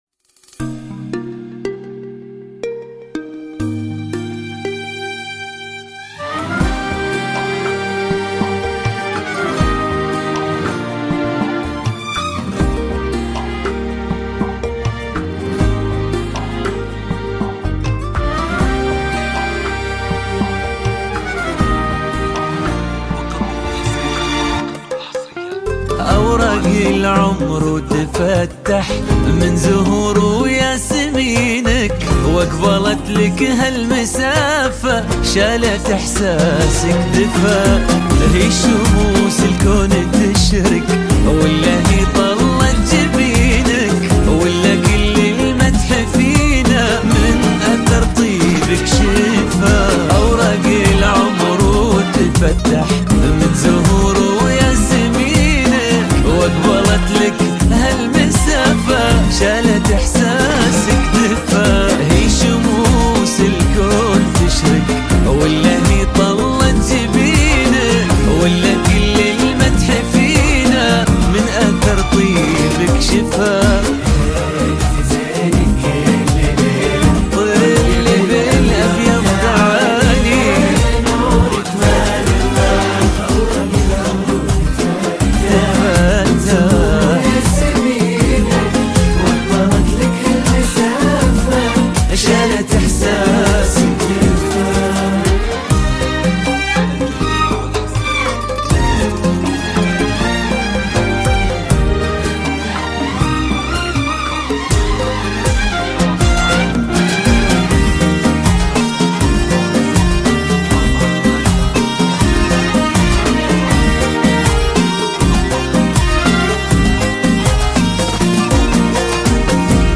زفات كوشة بدون اسماء